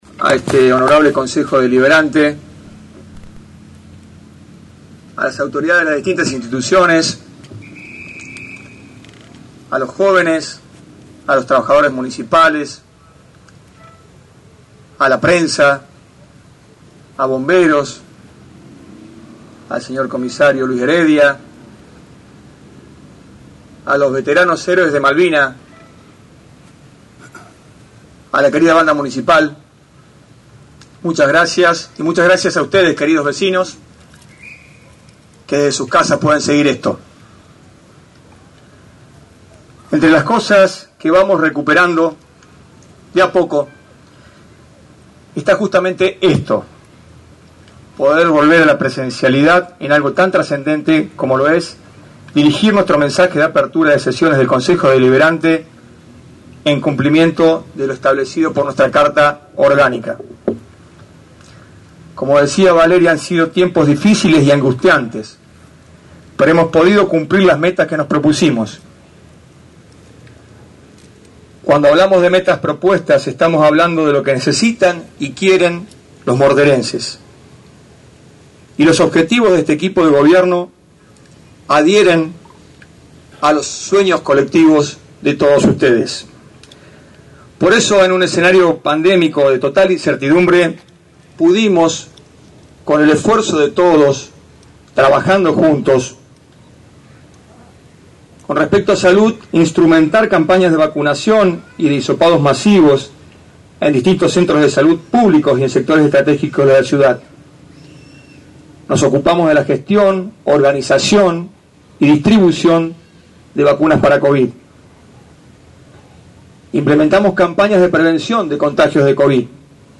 En la explanada del FFCC se realizó el Acto Protocolar y Mensaje Anual del intendente municipal Dr. José Bria en la Apertura del Período de Sesiones Ordinarias del Concejo  Deliberante.